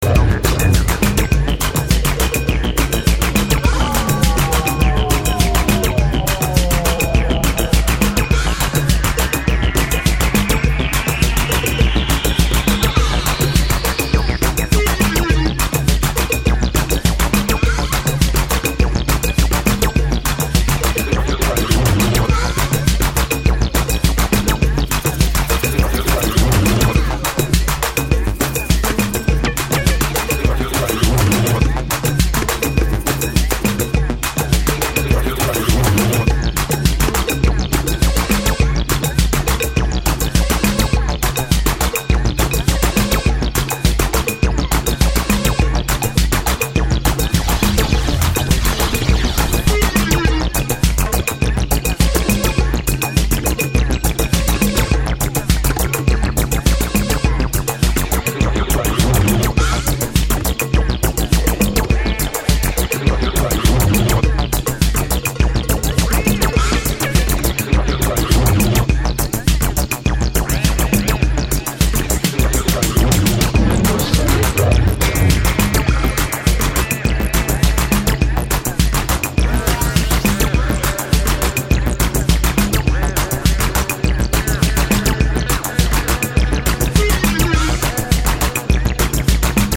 Fantastic excursions in EBM/tribal/krautrock
Electronix House